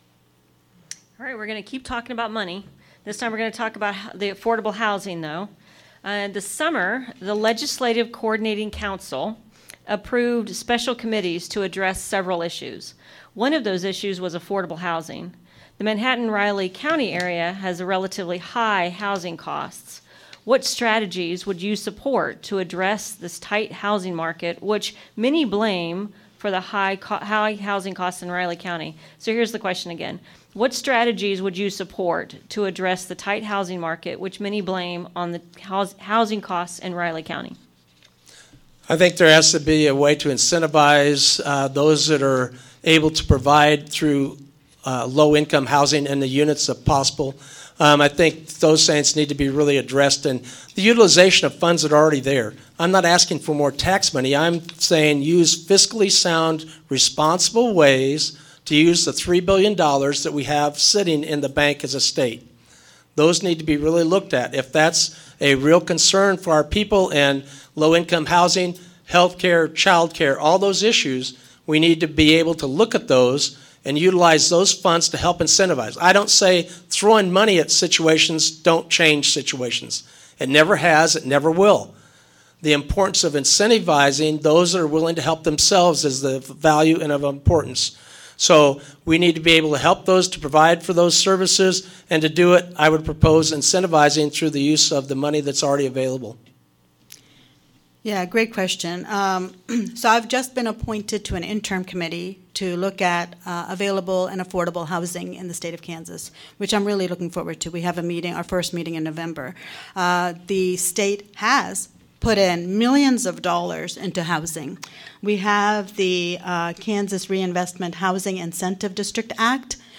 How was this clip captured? Legislative candidates for this fall’s elections participated in a forum at the Manhattan Public Library Saturday, October 5.